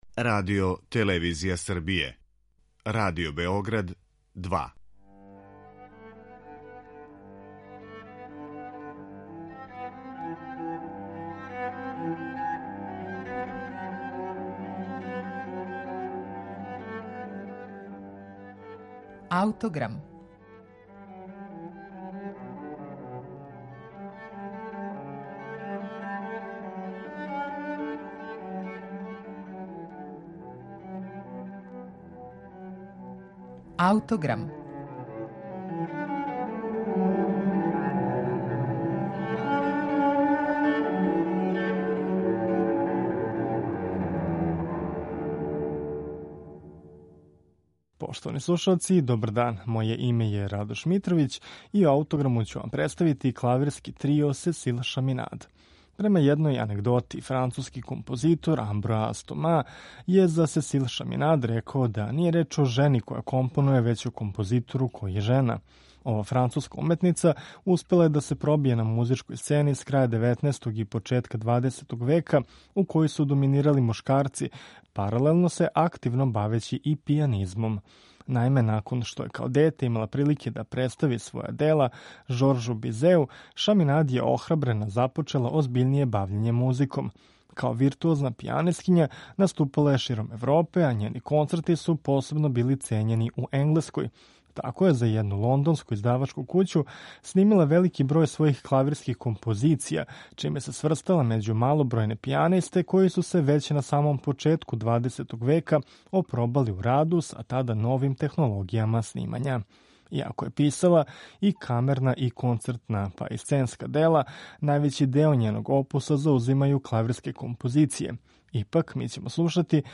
Ипак, написала је и известан број камерних композиција, од којих се издвајају клавирска трија. У емисији Аутограм, представићемо Други клавирски трио, из 1886. године, у интепретацији трија „Парнасус".